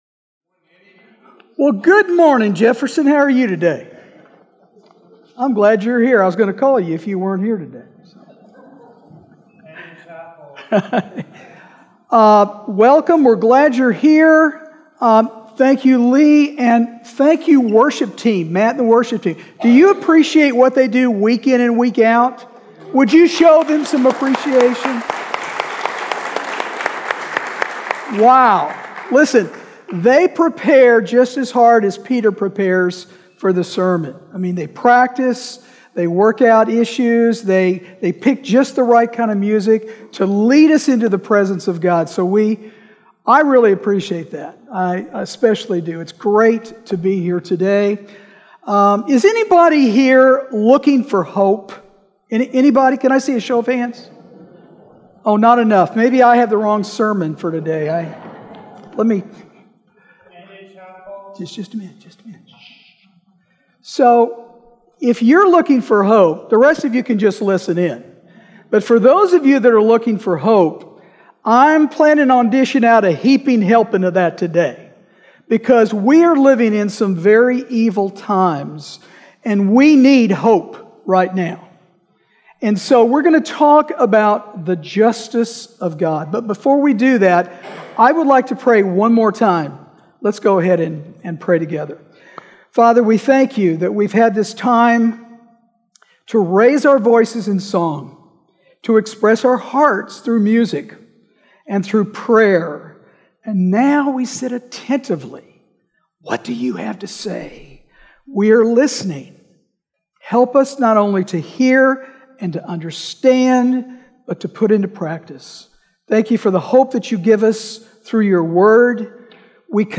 Sermon Detail
October_13th_Sermon_Audio.mp3